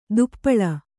♪ duppaḷa